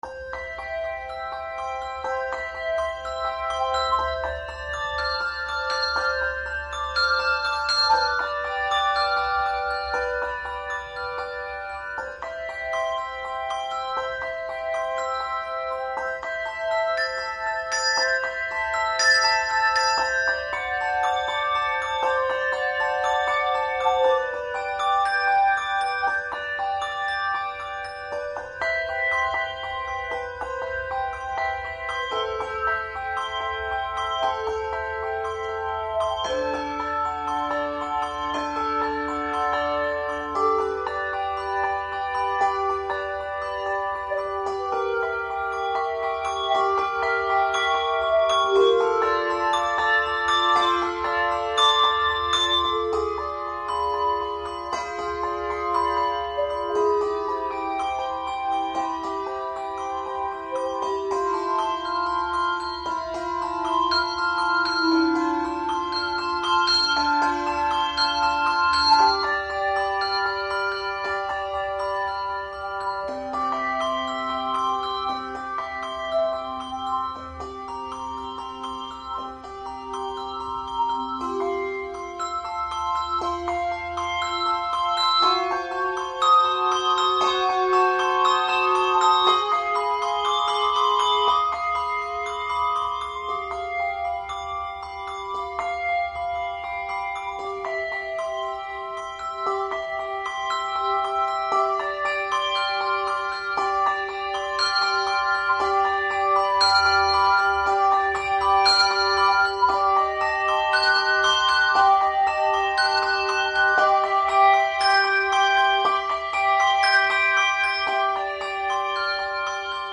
Handbell Quartet
Genre Sacred
No. Octaves 4 Octaves